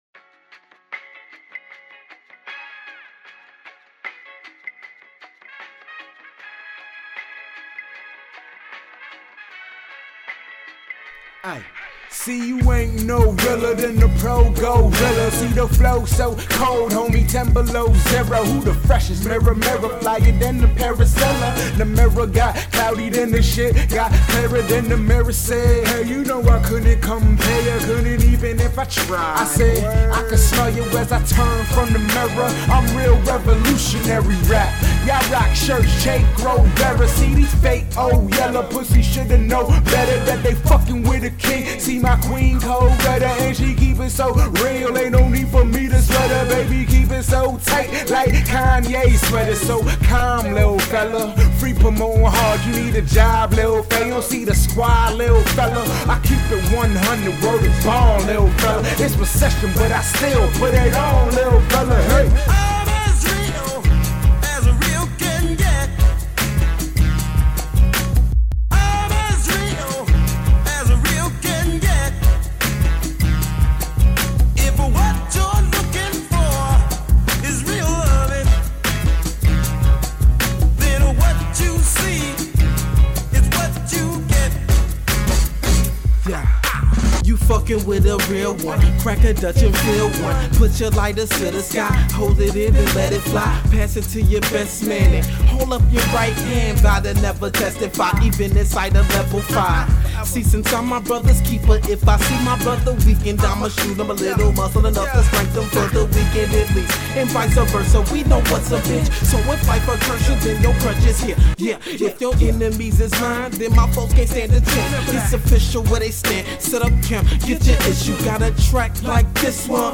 Hip-Hop
which consists of four MC’s from the southeast